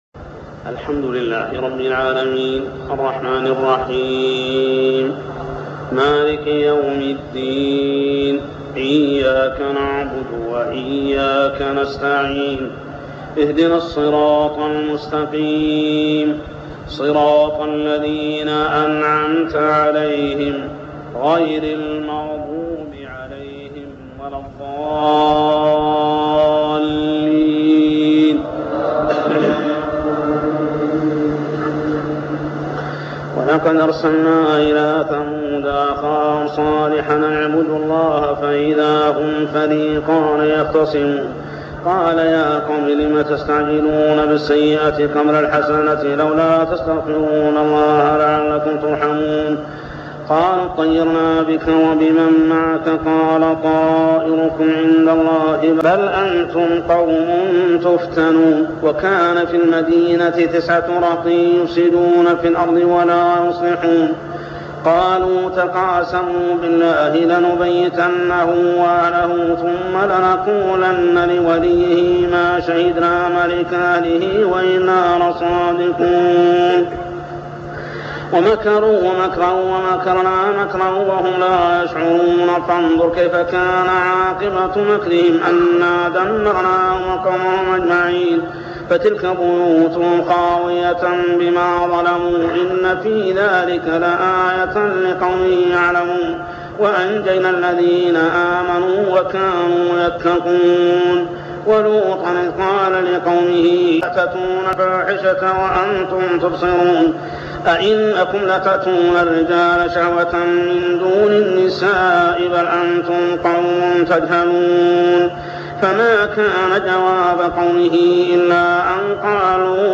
صلاة التراويح عام 1402هـ سورتي النمل 45-93 و القصص 1-50 | Tarawih prayer Surah An-Naml and Al-Qasas > تراويح الحرم المكي عام 1402 🕋 > التراويح - تلاوات الحرمين